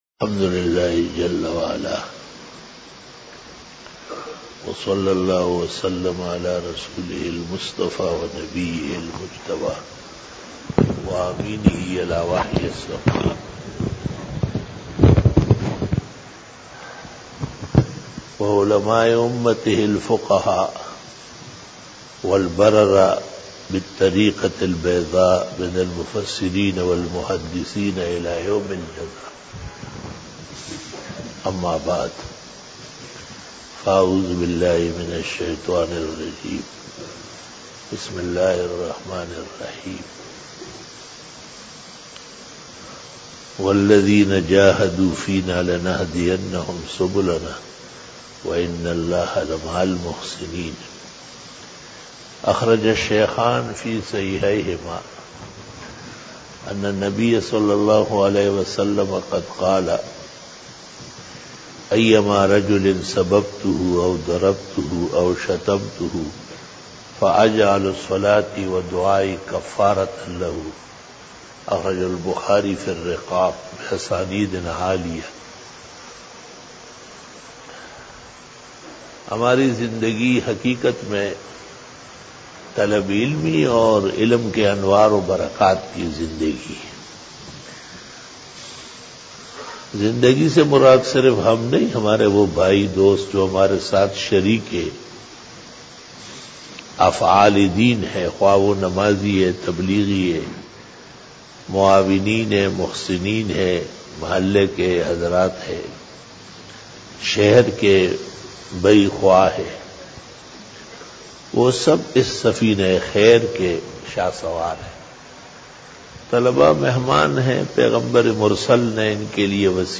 After Isha Byan
بیان بعد نماز عشاء بروز جمعہ